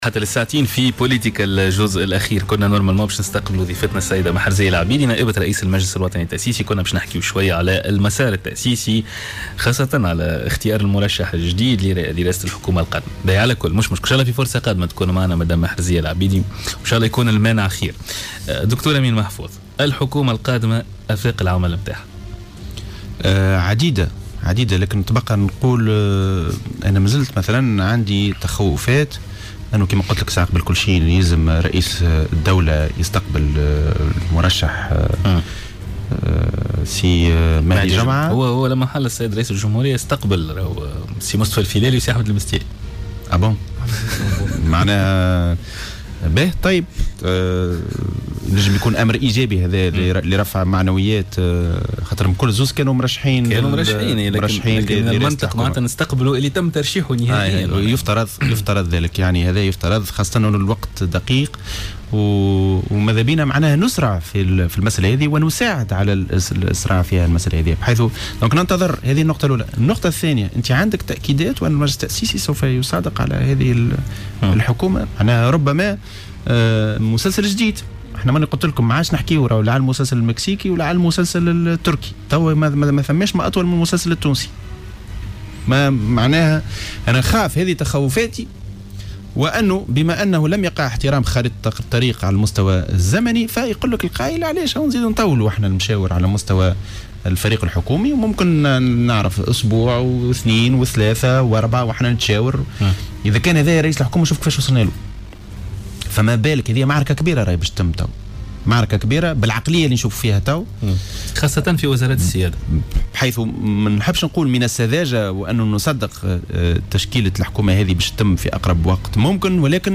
مداخلة له في برنامج" بوليتيكا"